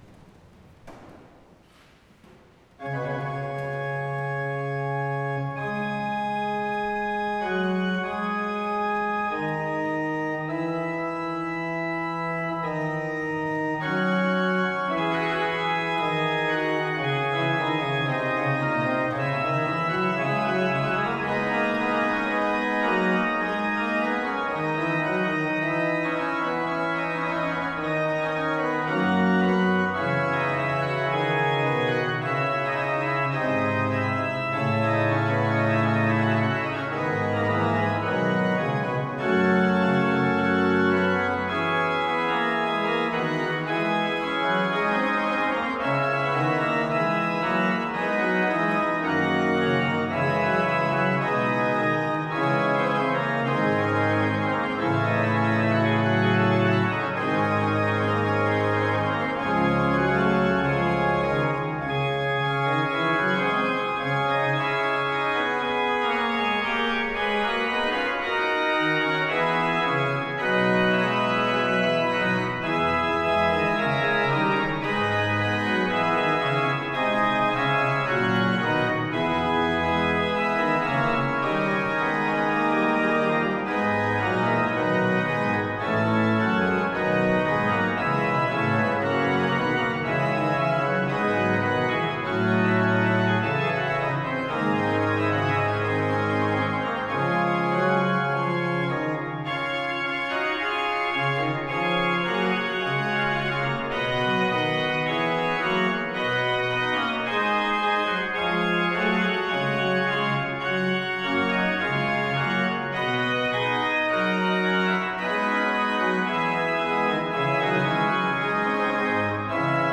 Avec l’autorisation de RTS-Espace 2, vous pouvez ici réécouter le concert des Fontaines d’Israël de Schein donné à la collégiale de St-Ursanne en 2018.